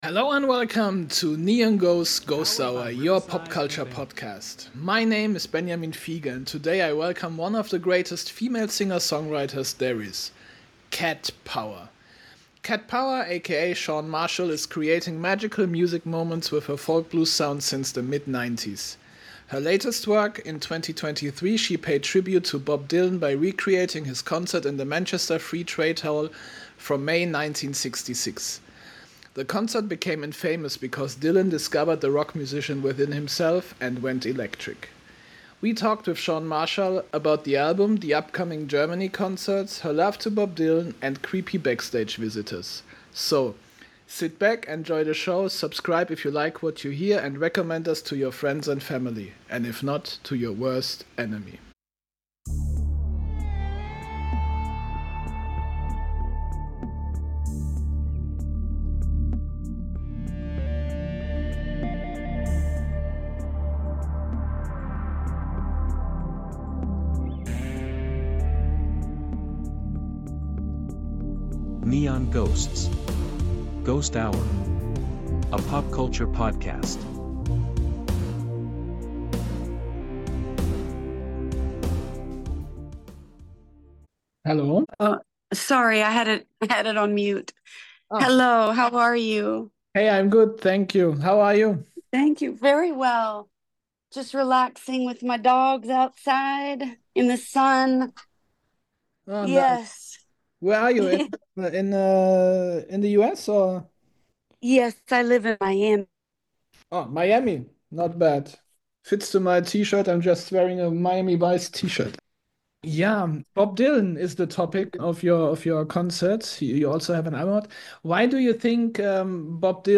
Im Interview: Cat Power - My Love to Bob Dylan ~ NEON GHOSTS: GHOST HOUR Podcast